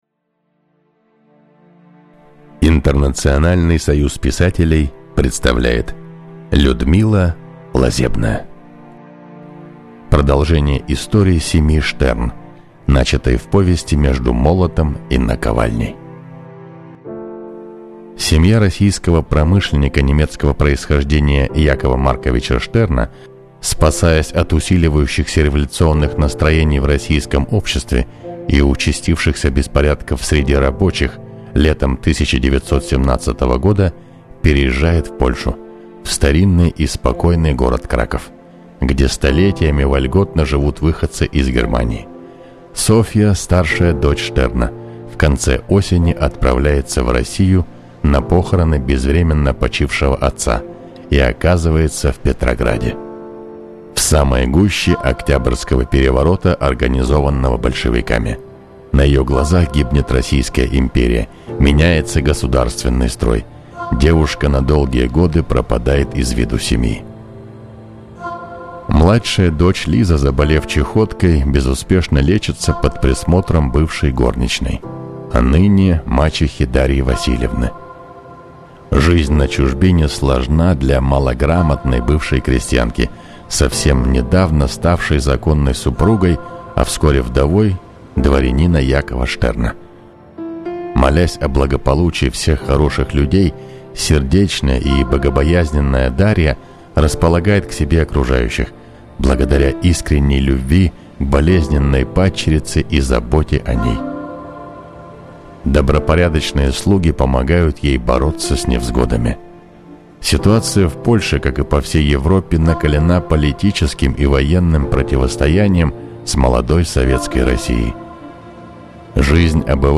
Аудиокнига Чужбина | Библиотека аудиокниг
Прослушать и бесплатно скачать фрагмент аудиокниги